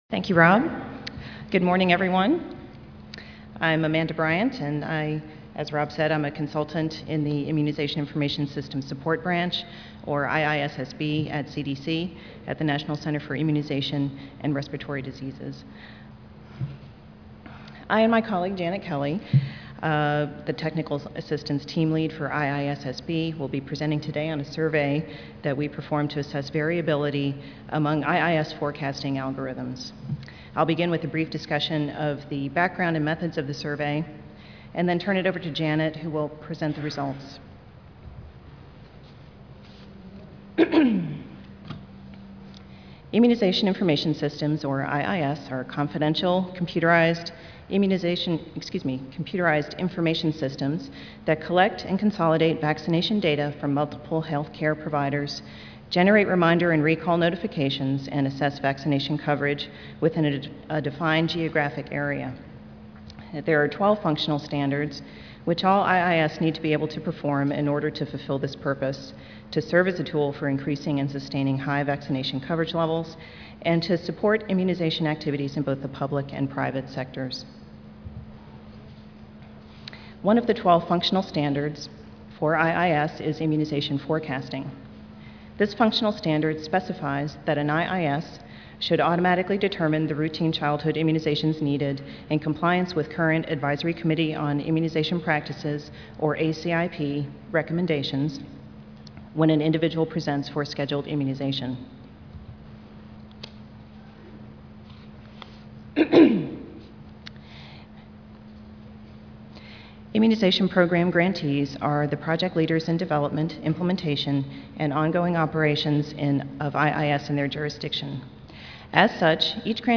MPH Audio File Recorded presentation